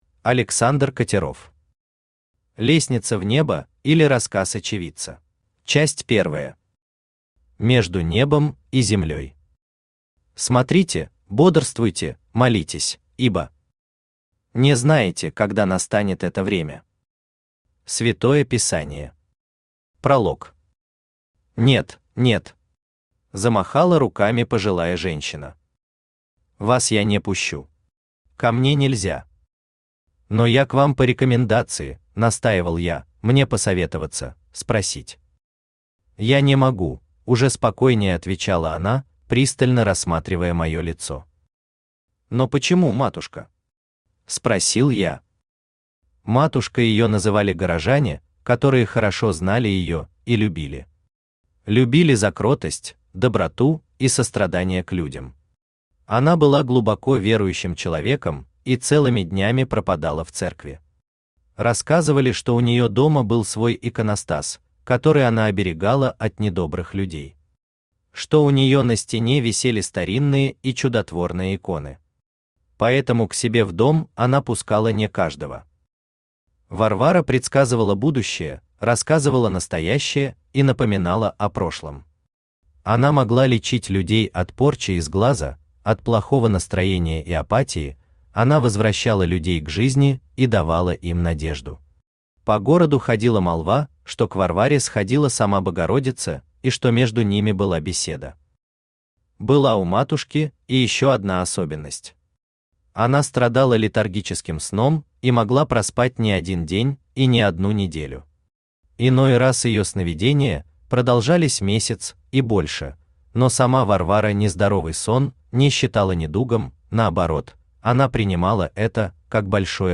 Aудиокнига Лестница в небо, или Рассказ очевидца Автор Александр Викторович Катеров Читает аудиокнигу Авточтец ЛитРес.